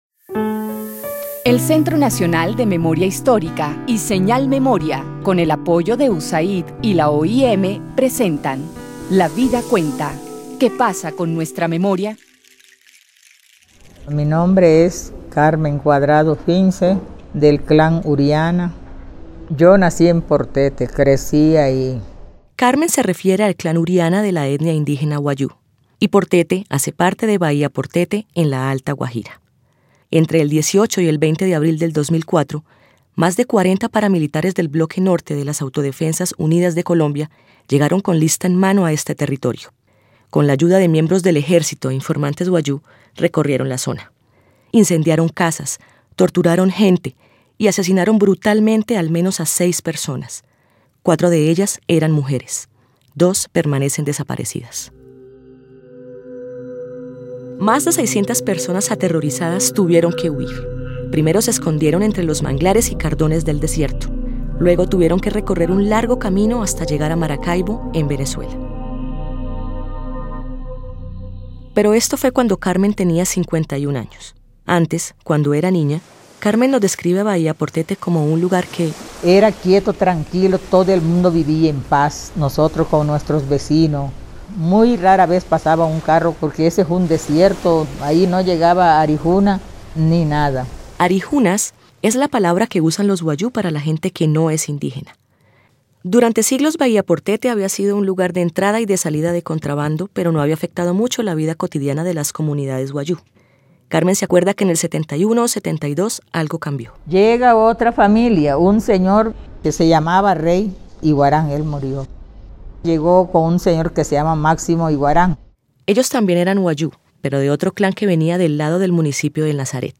(tomado de la fuente) Audiencia (dcterms:audience) General Descripción (dcterms:description) Serie radial basada en el informe ¡Basta ya! Colombia: memorias de guerra y dignidad.